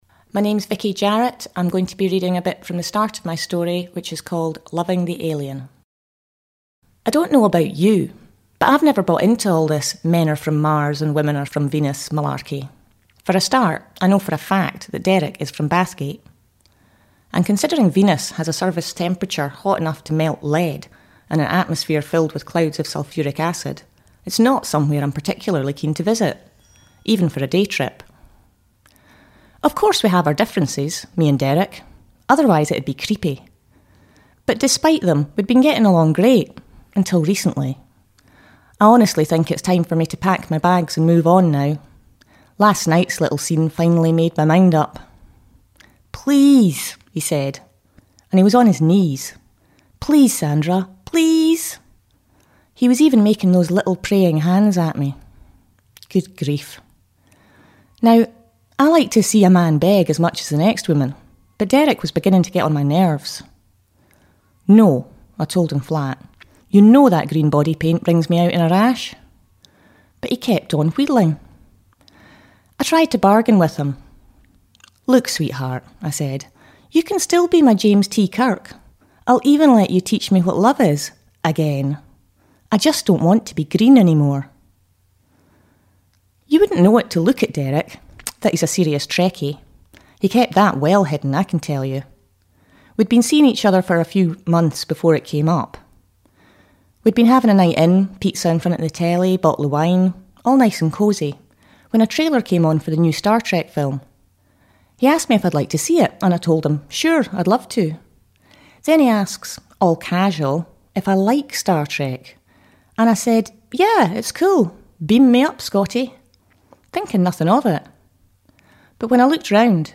reads an excerpt from the story